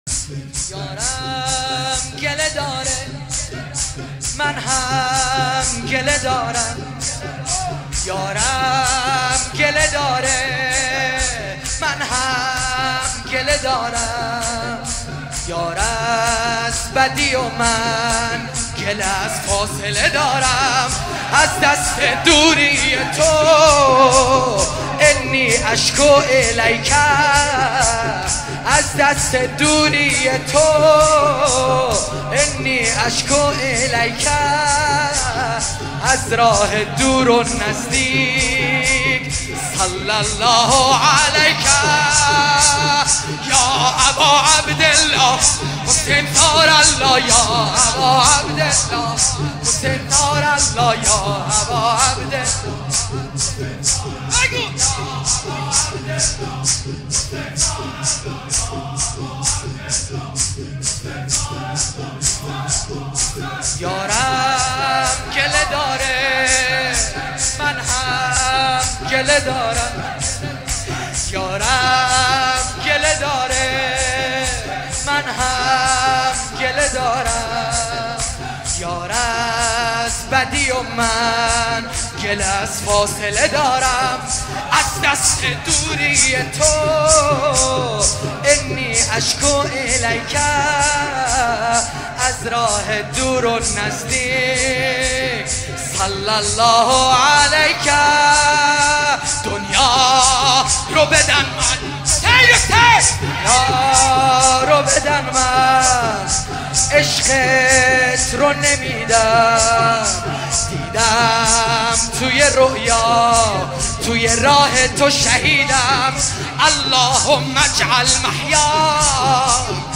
شور | یارم گله داره من هم گله دارم
مداحی
در شهادت امام محمد باقر(ع)